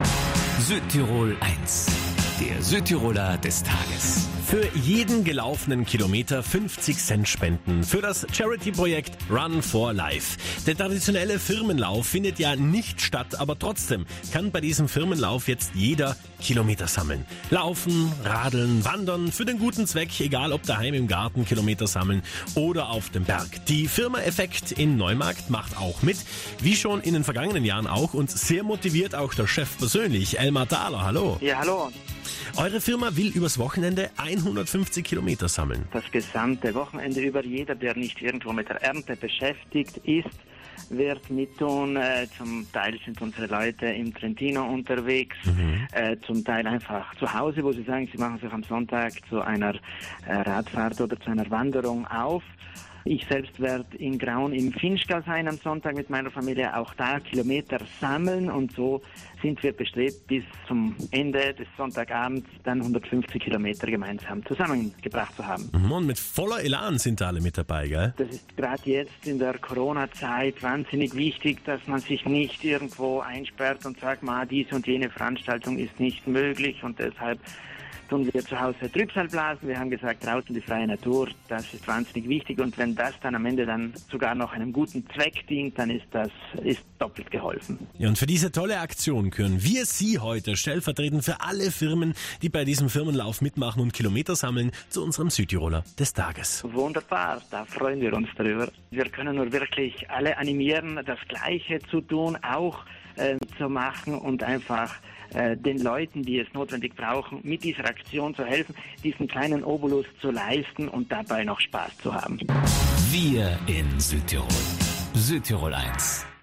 Wir haben mit einem der Läufer gesprochen: